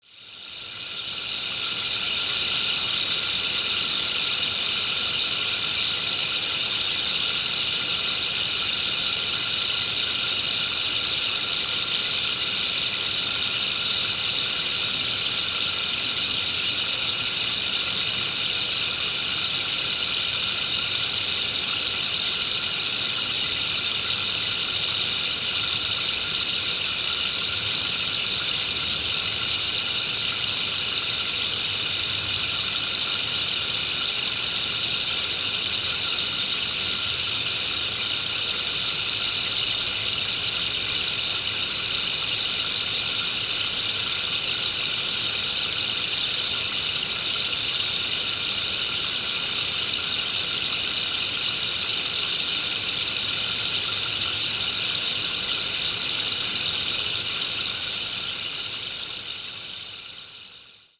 Der Meinl Sonic Energy Rainmaker ist aus hochwertigem, lackiertem Holz gefertigt und erzeugt einen beruhigenden, natürlichen Regensound, der tief…
Seine sanften, fließenden Klänge machen ihn zum perfekten Klanghintergrund für Klangbäder, Klangreisen, Yoga-Sessions und Meditationen, wobei gleichzeitig andere Instrumente gespielt werden können.